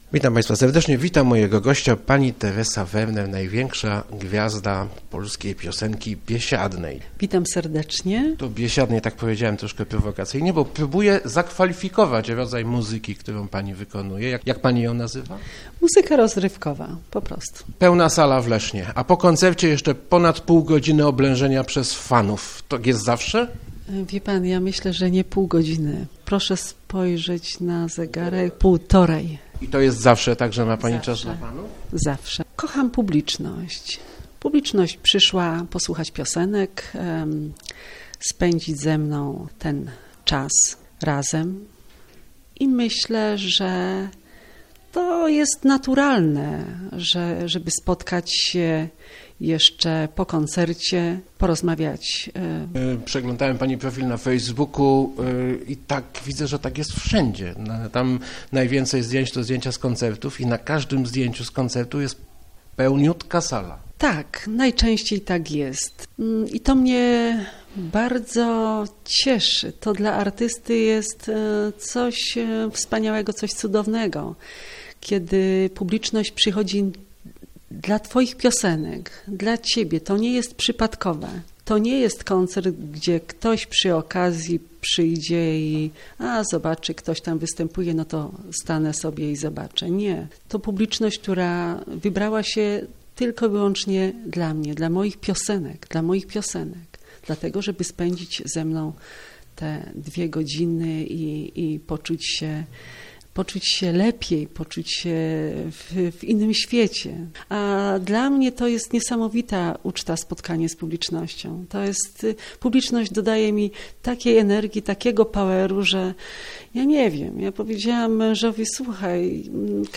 twerner.jpg-Nie przeszkadza mi, że się mnie nazywa "gwiazdą disco polo", choć mówią tak osoby, które nie mają pojęcia o muzyce - mówiła w Rozmowach Elki Teresa Werner. Piosenkarka, wywodząca się z zespołu "Śląsk" bije rekordy popularności, koncertując przy pełnych salach.